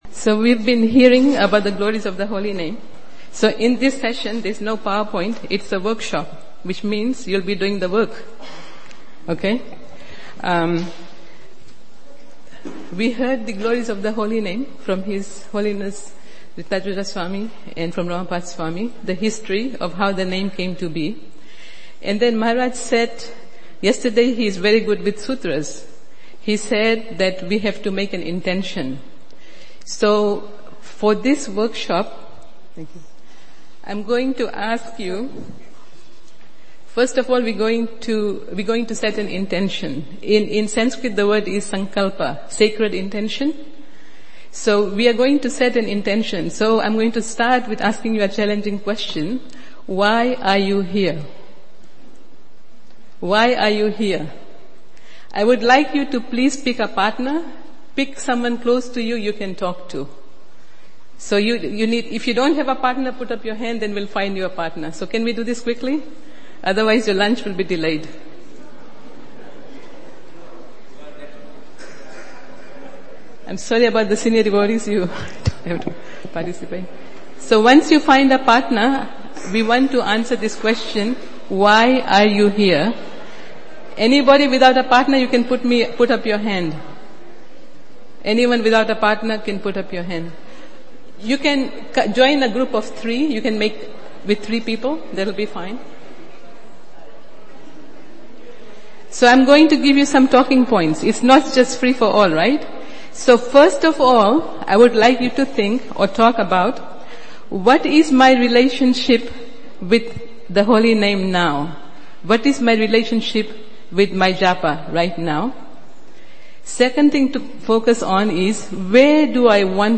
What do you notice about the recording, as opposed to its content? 2018 Houston Japa Retreat